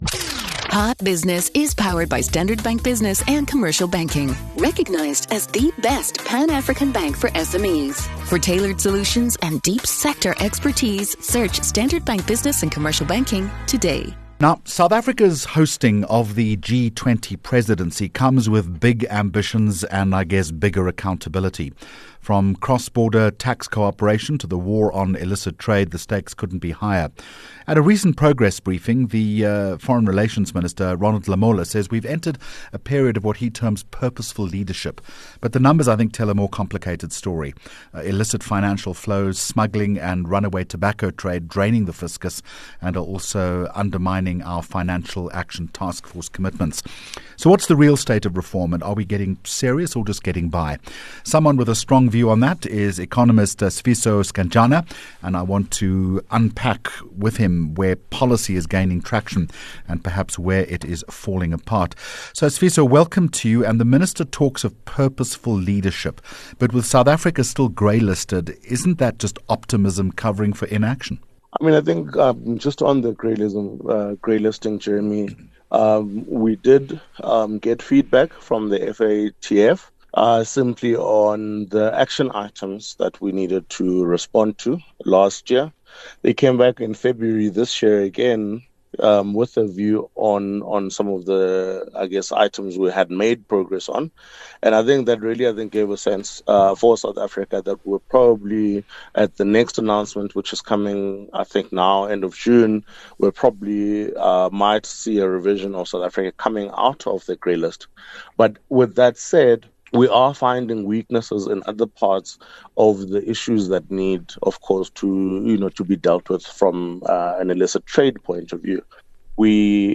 10 Jun Hot Business Interview